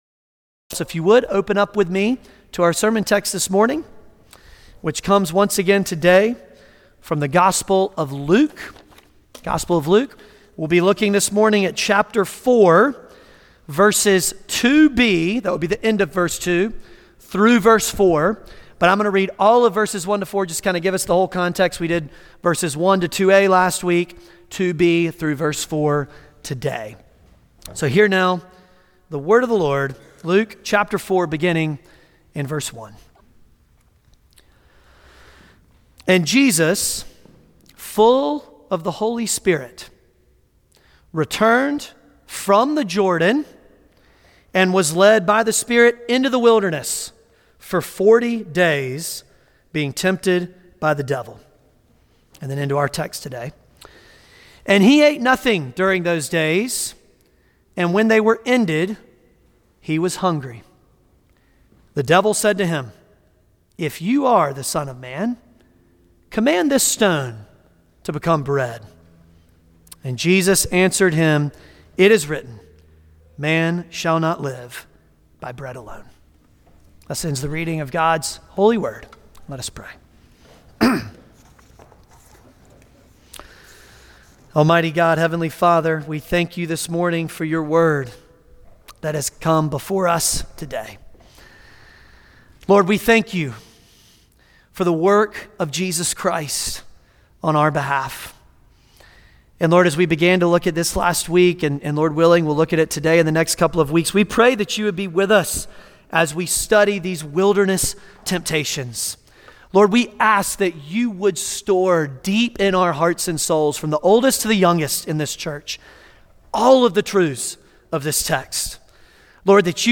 Luke 4:2-4 Service Type: Sunday Morning Luke 4:2-4 « An Offensive Strike The Narrow Gate